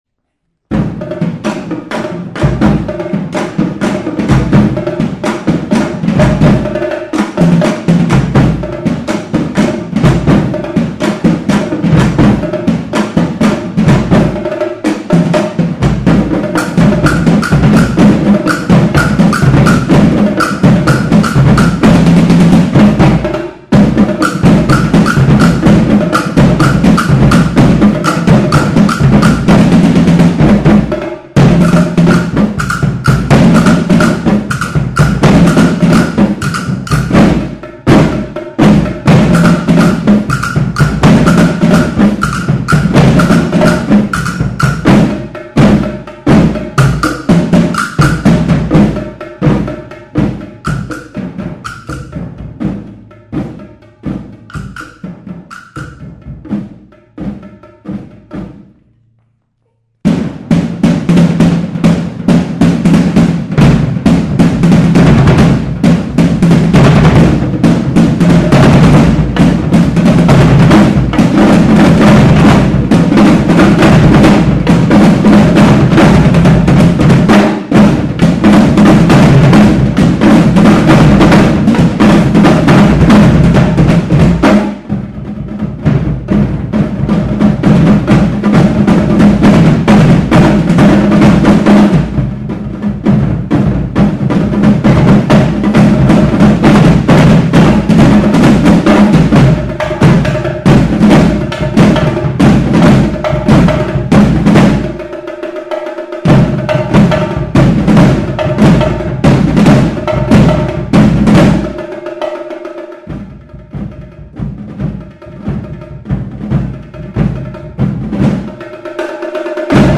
Junior Percussion